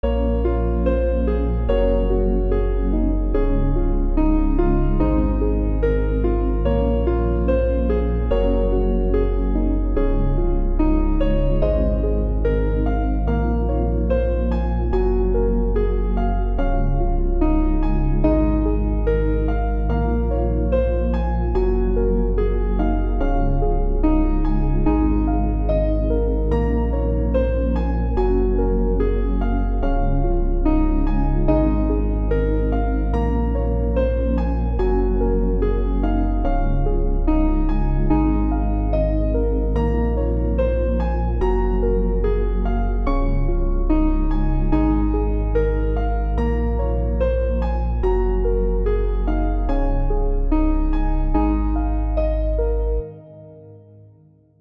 Piano melody
I made this piano melody (with some accompanying strings) as background music for a tycoon game that I was working on.